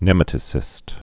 (nĕmə-tə-sĭst, nĭ-mătə-)